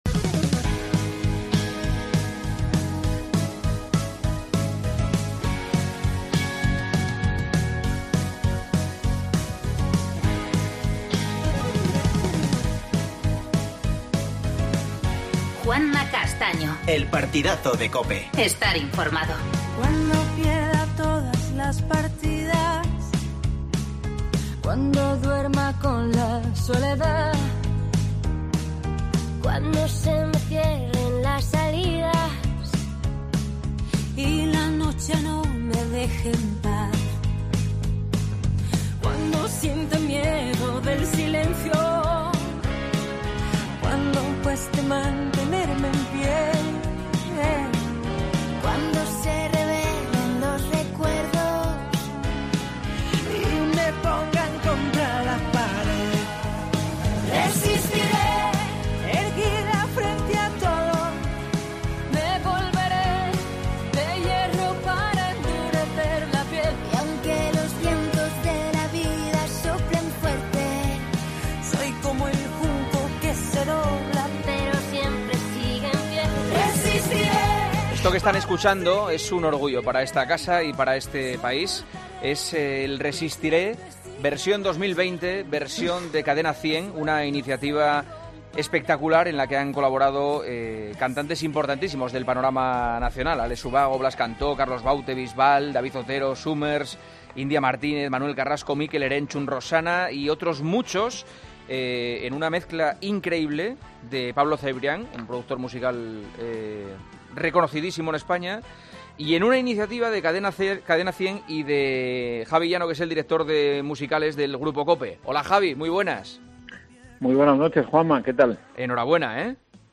entrevistamos a José Antonio Camacho.